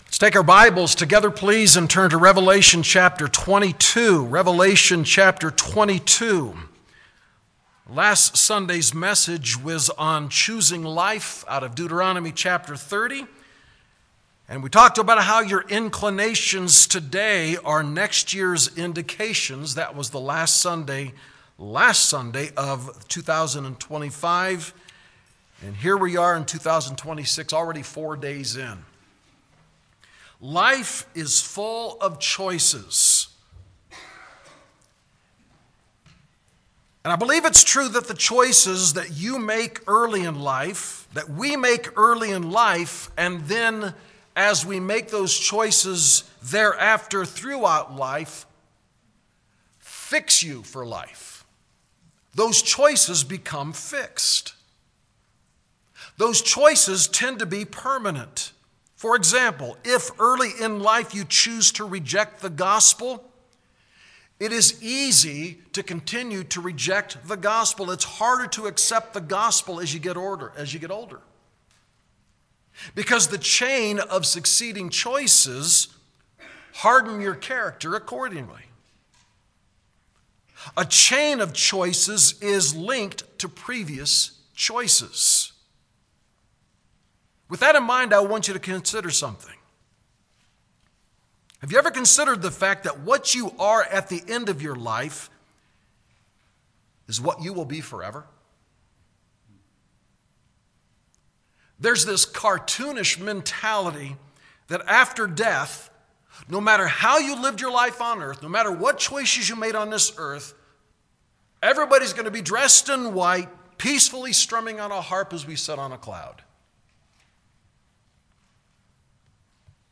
Sermon: Forever Fixed ‘As You Were’